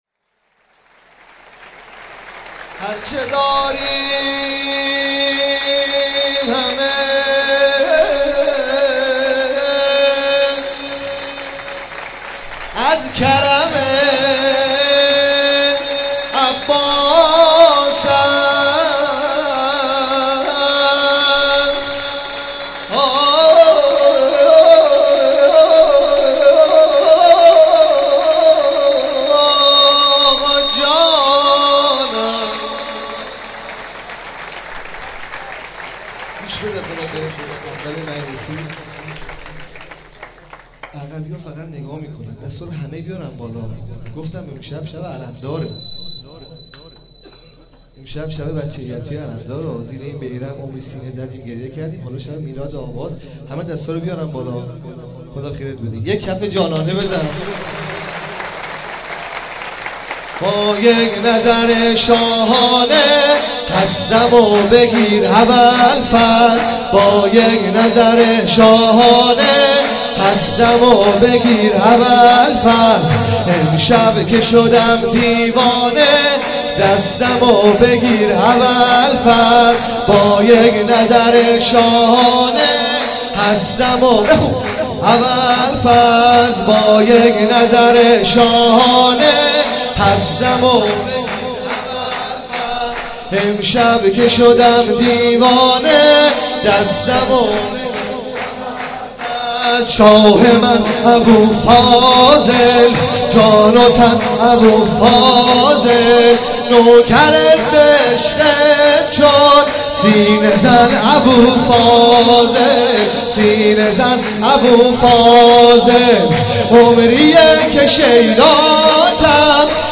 مراسم شب میلاد حضرت عباس(ع) 96 :: هیئت علمدار
حسینیه حضرت زینب (سلام الله علیها)